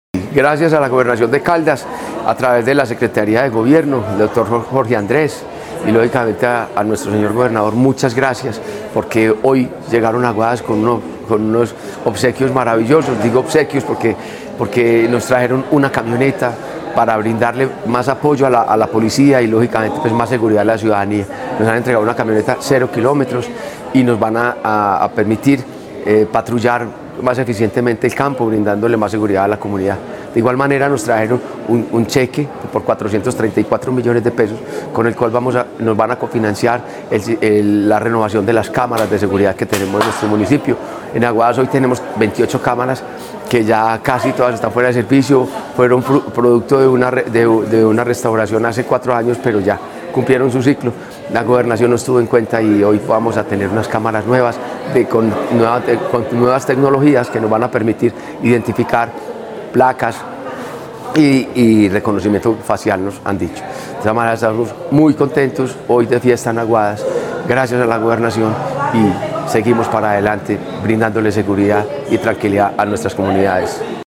Fabio Gómez Mejía, alcalde de Aguadas.
Fabio-Arias-Gomez-alcalde-de-Aguadas.mp3